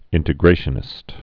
(ĭntĭ-grāshə-nĭst)